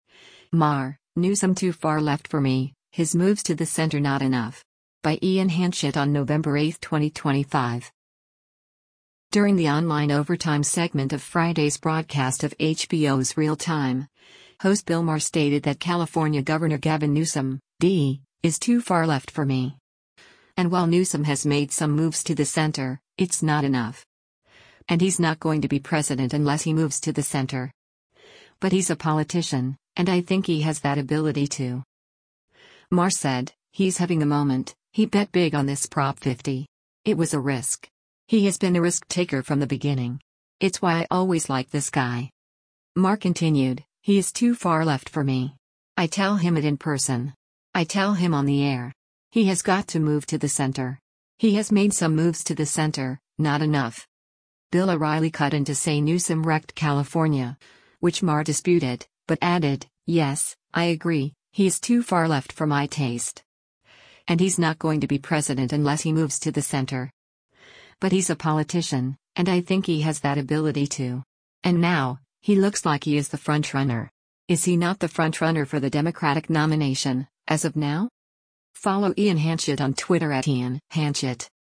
During the online “Overtime” segment of Friday’s broadcast of HBO’s “Real Time,” host Bill Maher stated that California Gov. Gavin Newsom (D) “is too far left for me.”
Bill O’Reilly cut in to say Newsom “wrecked” California, which Maher disputed, but added, “yes, I agree, he is too far left for my taste.